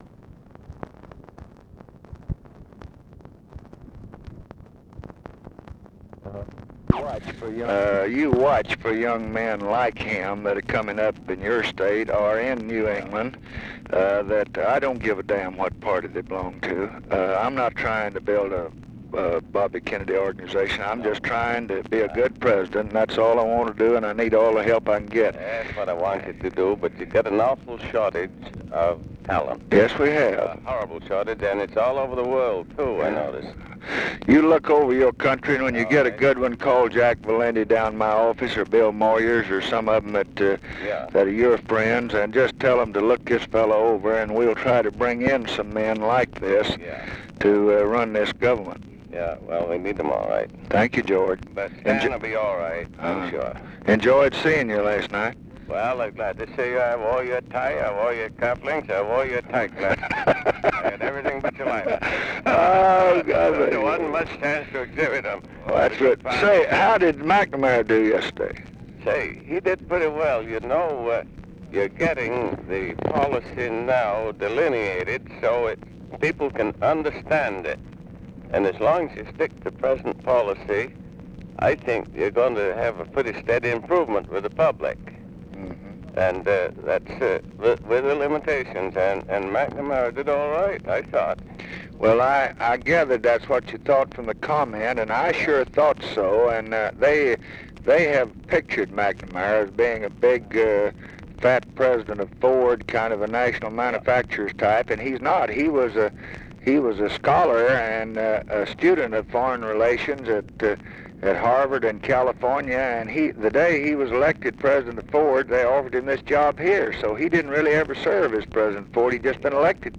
Conversation with GEORGE AIKEN, March 4, 1966
Secret White House Tapes